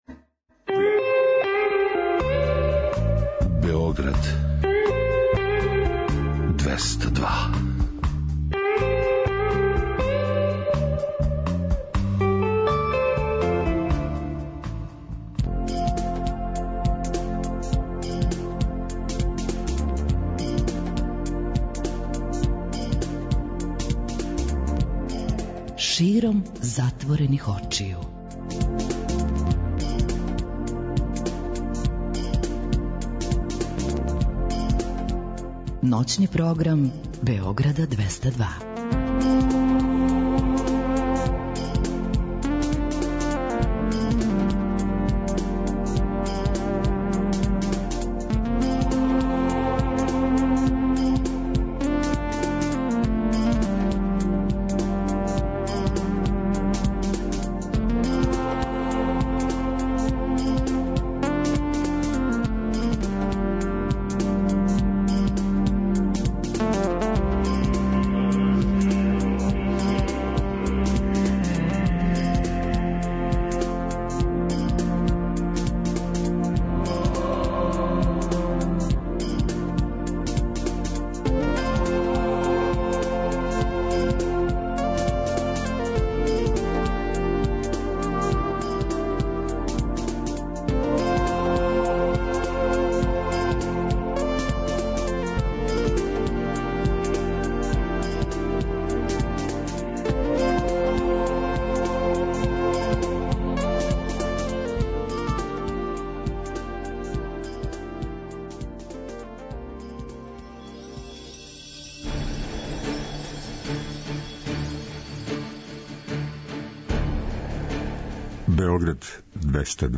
Ноћни програм Београда 202.
Тако некако изгледа и колажни контакт програм "САМО СРЦЕМ СЕ ДОБРО ВИДИ".